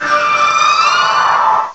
cry_not_froslass.aif